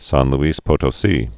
(sän l-ēs pōtō-sē)